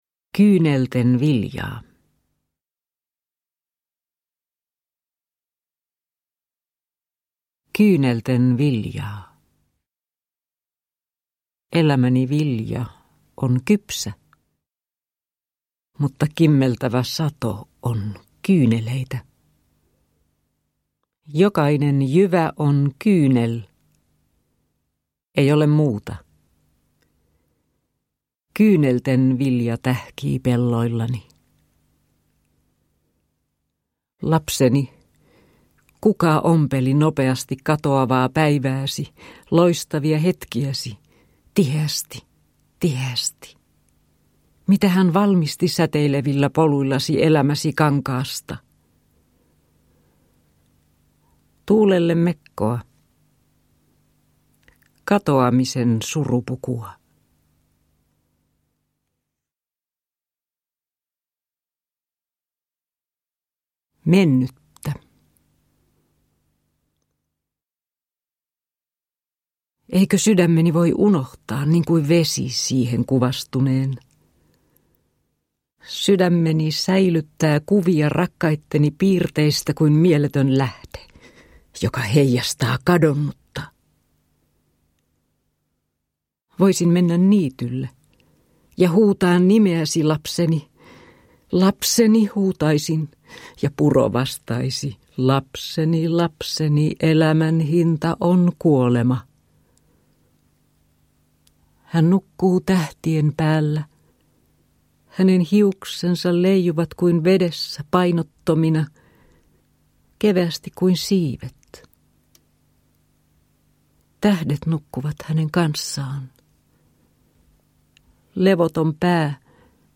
Sukupolveni unta – Ljudbok – Laddas ner